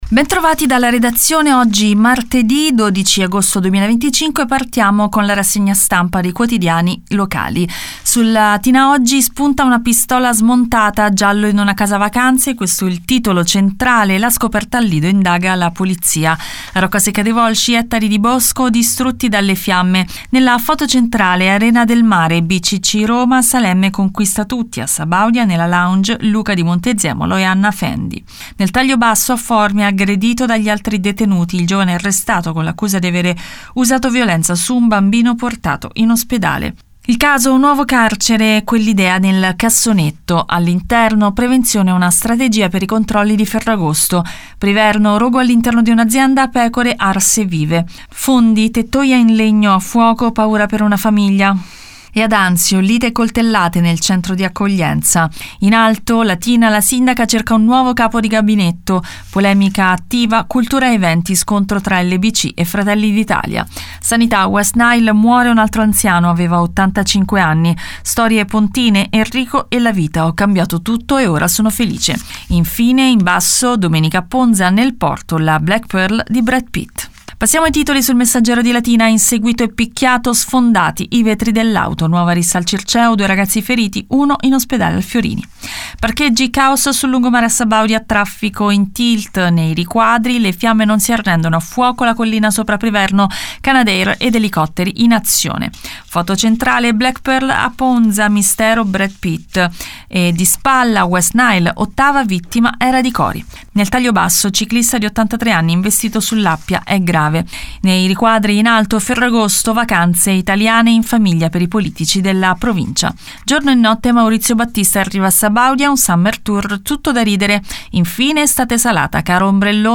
LATINA – Qui trovate Prima Pagina, in un file audio di pochi minuti, i titoli di Latina Editoriale Oggi e del Messaggero Latina.